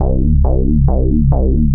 Index of /90_sSampleCDs/Club_Techno/Bass Loops
BASS_137_C.wav